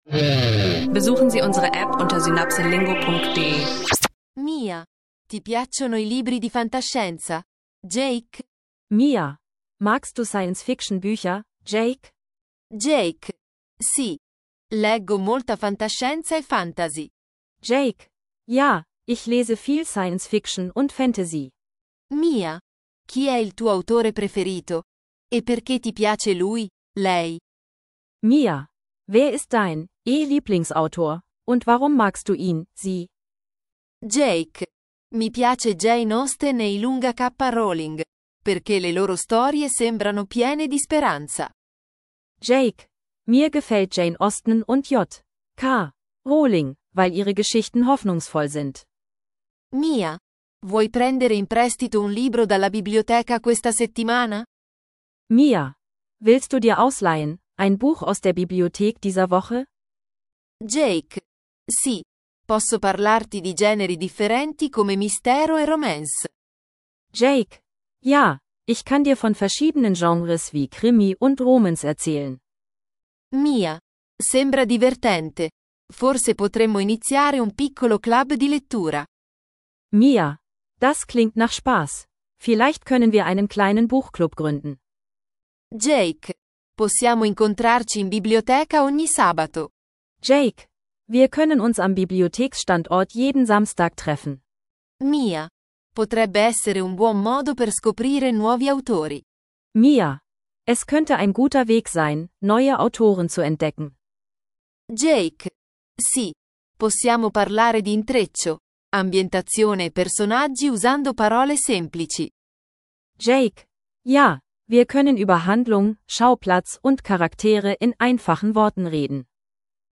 Kurzdialoge zu Büchern, Autoren und literarischen Gattungen – Italienisch lernen im Alltag mit SynapseLingo.